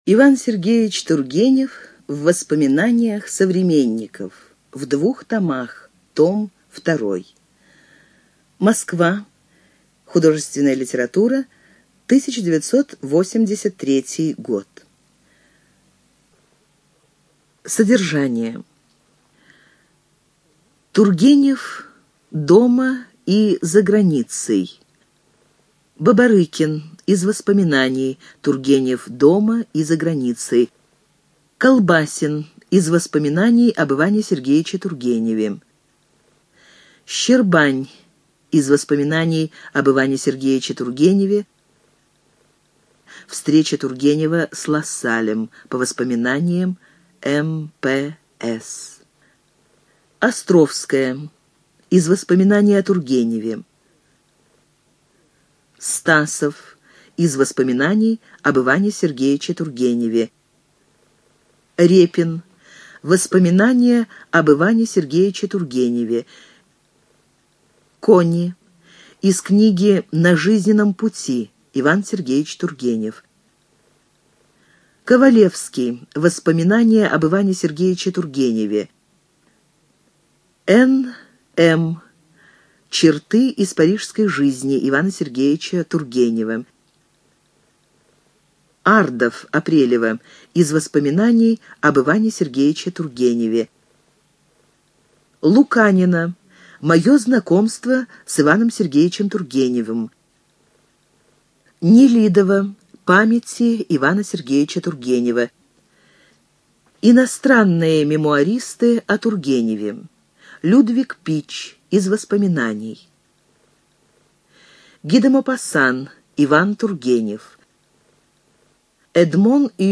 ЖанрБиографии и мемуары
Студия звукозаписиЛогосвос